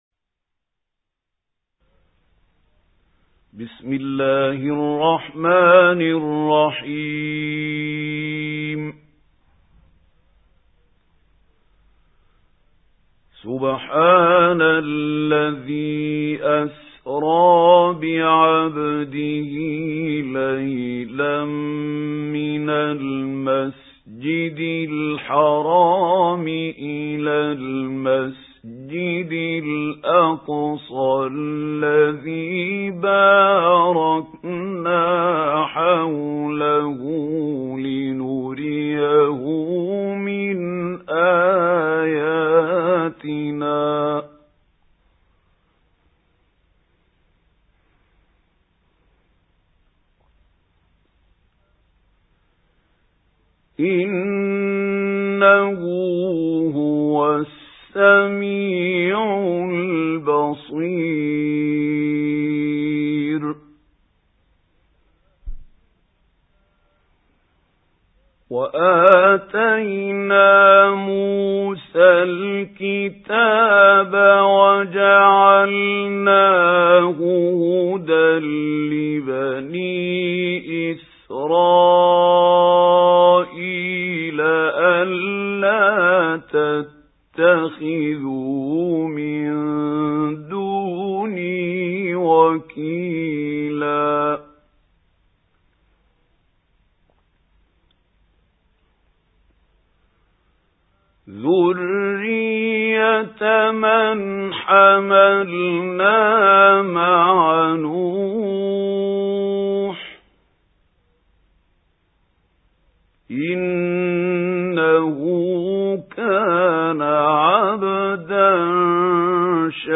سُورَةُ الإِسۡرَاءِ بصوت الشيخ محمود خليل الحصري